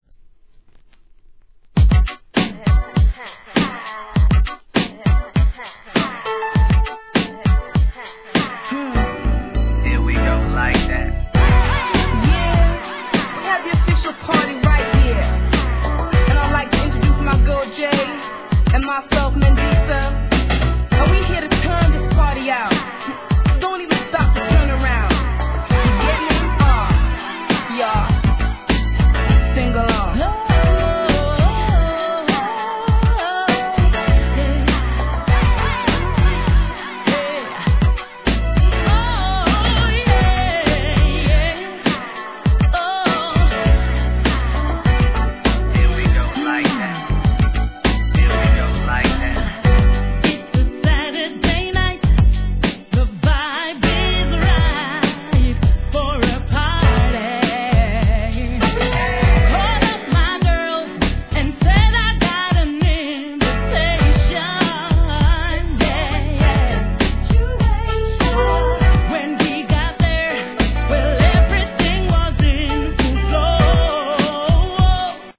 HIP HOP/R&B
良作R&B!!